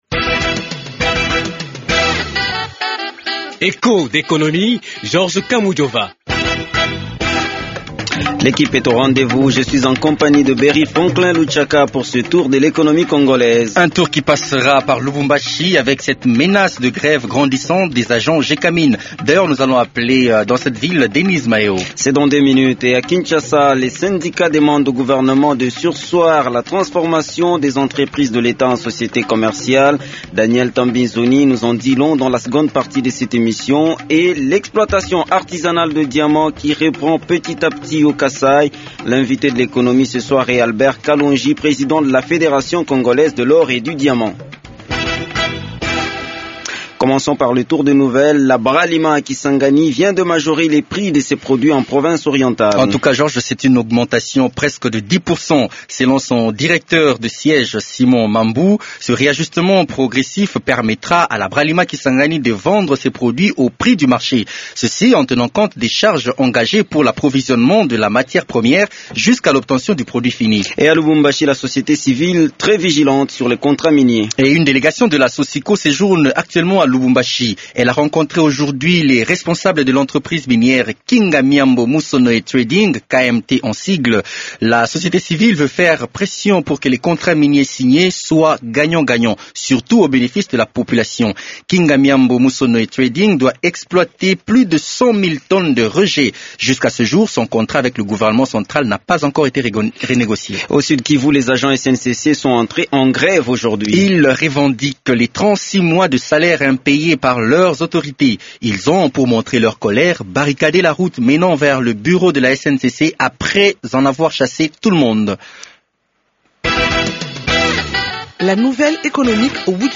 Télécharger La Gécamines Les mangeurs de cuivre promettent d’aller en grève dans 4 jours si l’Etat ne donne pas 100 millions de dollars. Le téléphone de l’émission sonne dans la capitale du cuivre pour connaitre les dessous de cette situation. A Kinshasa, les syndicats demande au gouvernement de surseoir la transformation des entreprises publiques en sociétés commerciales.